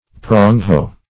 Prong-hoe \Prong"-hoe`\, n. A hoe with prongs to break the earth.